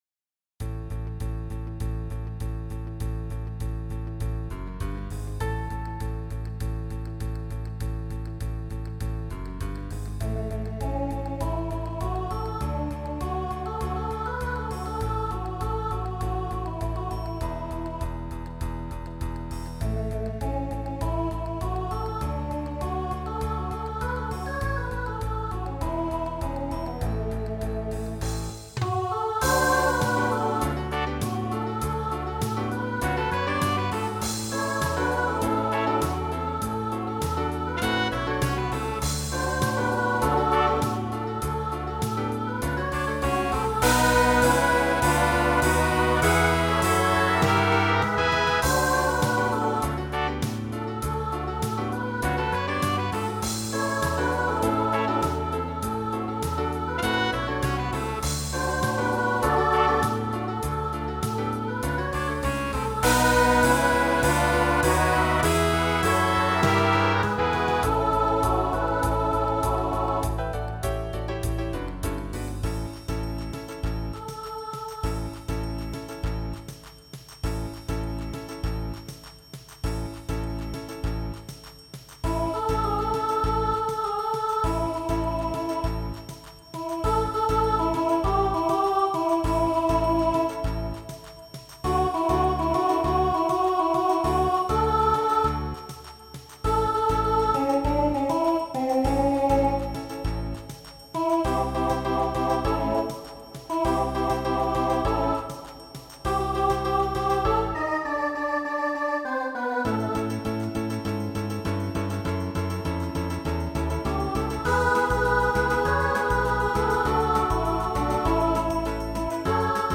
Voicing SSA Instrumental combo Genre Country
Mid-tempo